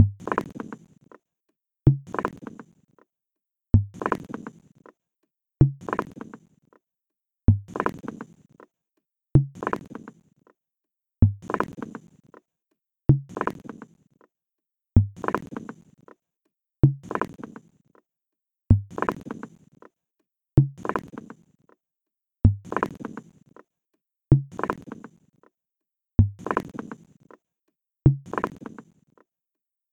Inciso 2 – en 5/4